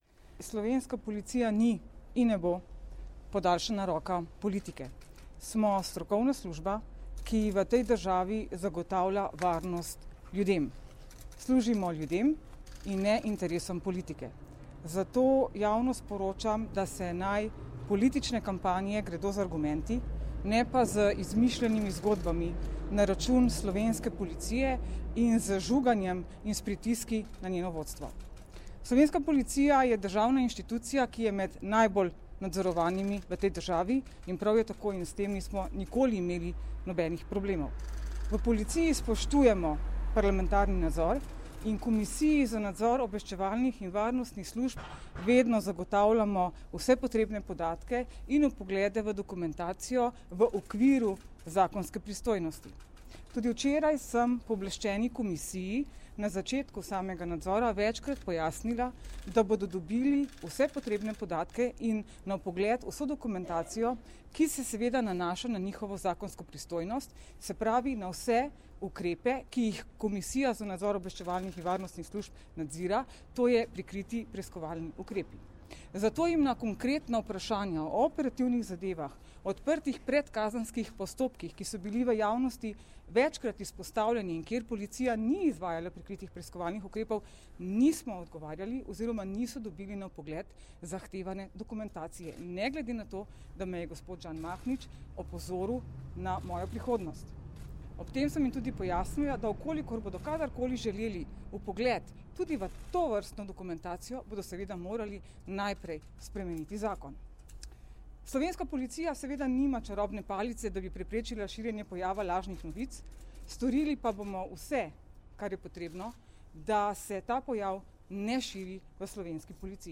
Zvočni posnetek izjave mag. Tatjane Bobnar